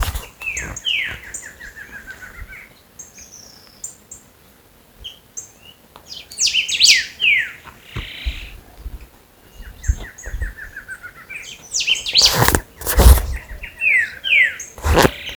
Choca Listada (Thamnophilus doliatus)
Nombre en inglés: Barred Antshrike
Localidad o área protegida: Parque Nacional El Impenetrable
Condición: Silvestre
Certeza: Fotografiada, Vocalización Grabada